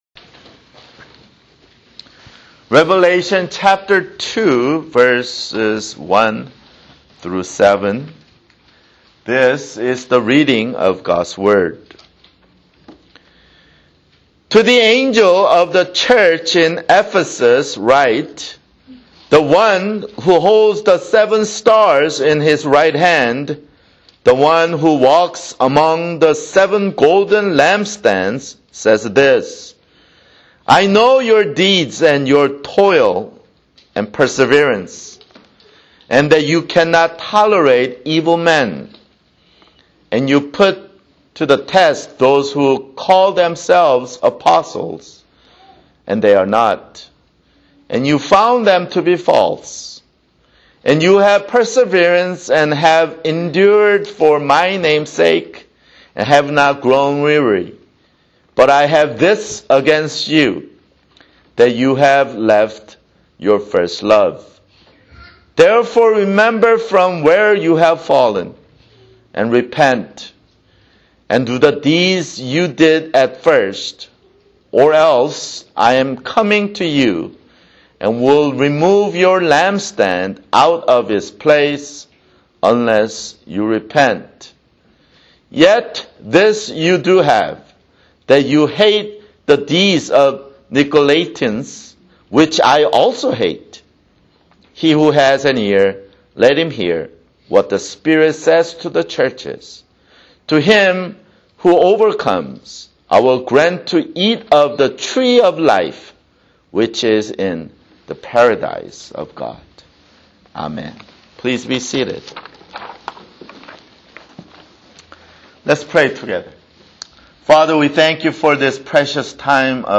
[Sermon] Revelation (12)